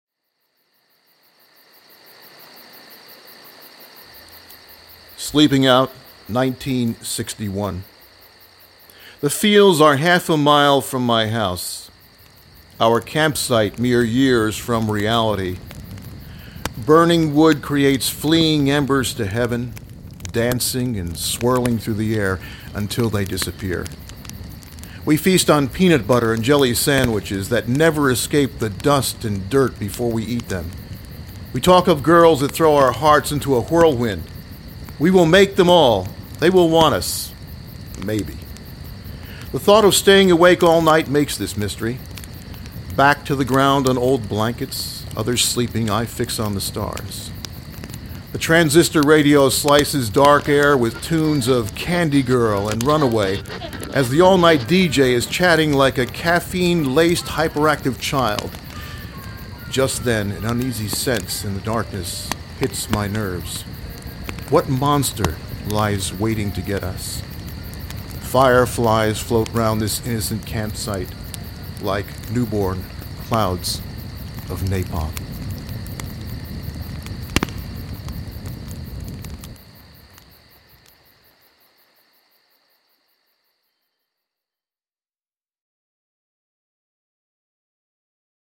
Click here for a reading by the poet